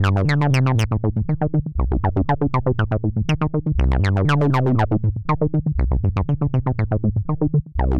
10个Osc Bass
描述：minator合成器，10个振荡器预置。
Tag: 120 bpm Electro Loops Bass Loops 1.35 MB wav Key : Unknown